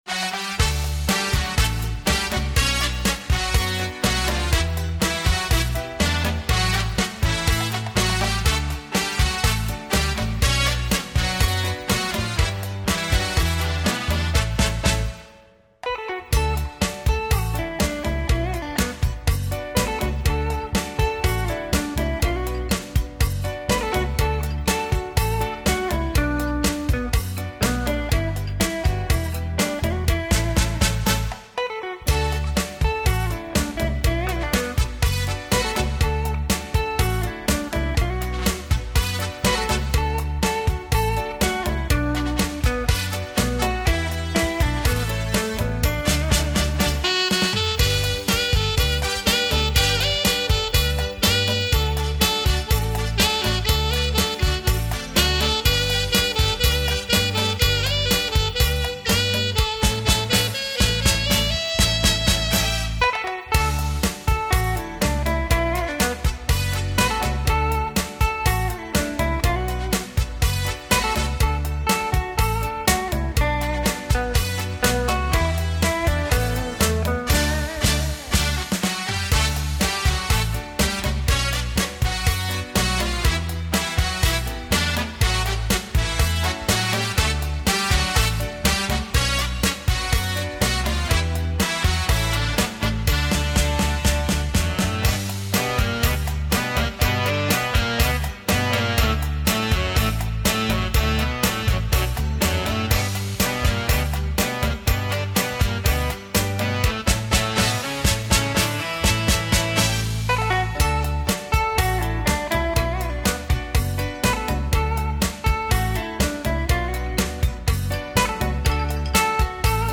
Ca sĩ: Không lời